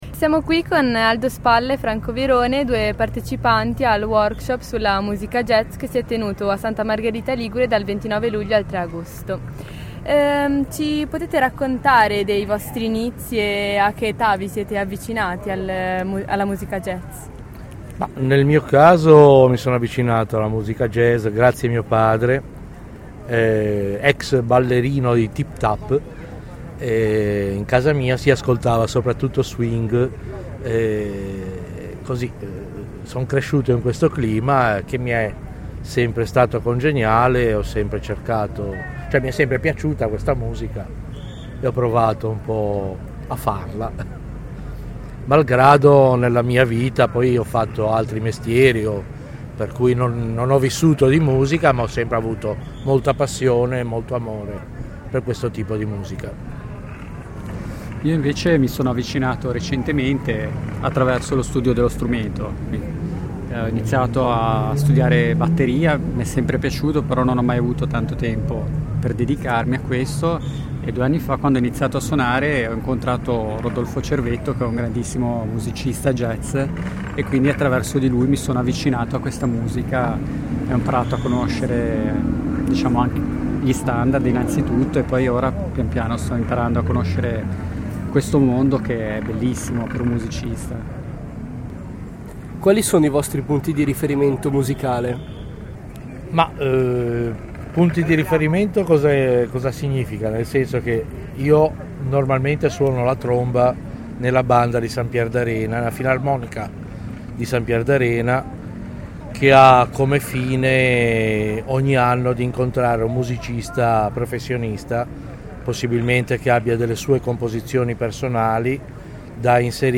Musicisti amatoriali di jazz
Intervista a due partecipanti al Workshop sulla musica Jazz tenutosi a Santa Margherita Ligure nell'ambito del festival Quotidianamente. I due partecianti, rispettivamente, chitarrista e batterista, ci raccontano questa esperienza di formazione e il loro punto di vista sulla musica jazz.